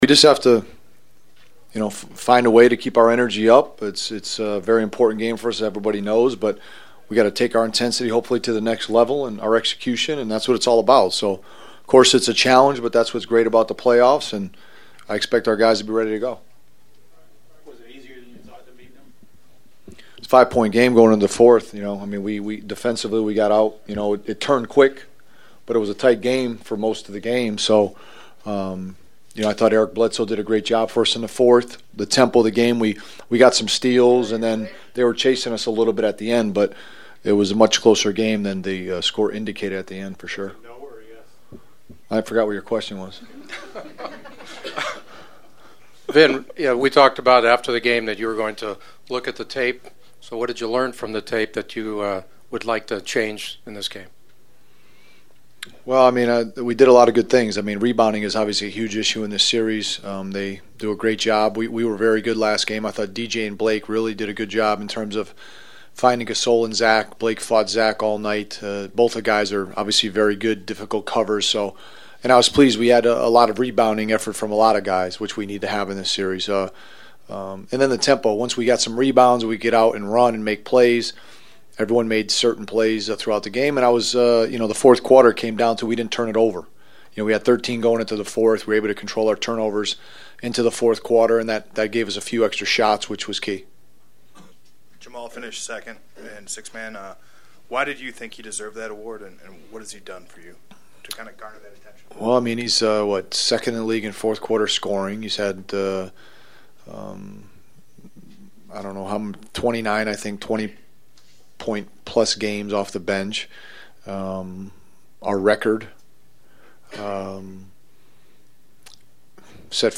We also got some pregame comments from Clippers coach Vinny Del Negro on tonight’s game and about his special 6th man falling short of the league award.